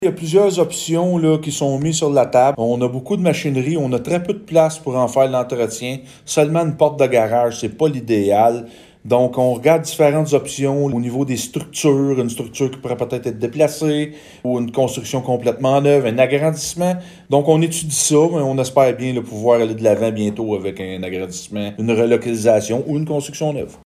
Gracefield prévoit dépenser plus de 7,6 millions $ au cours des prochaines années afin de mener à bien divers projets. Le plan triennal d’immobilisations 2024-2026 adopté par le conseil municipal prévoit investir plus de 1,5 million $ dans la réfection du réseau d’égouts puis 3 millions $ supplémentaires serviront à l’agrandissement du garage municipal. Le maire de Gracefield, Mathieu Caron, en parle plus en détail :